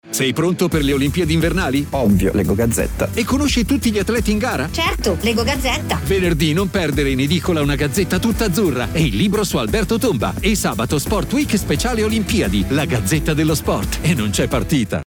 E’ uno degli Speaker Italiani fra i più ascoltati in televisione e in radio, particolarmente versatile l’abbiamo ascoltato sia per i toni “caldi” che per l’irresistibile freschezza e allegria.
La_Gazzetta_dello_Sport_Olimpiadi_Invernali_RADIO_15s_B_ONAIR.mp3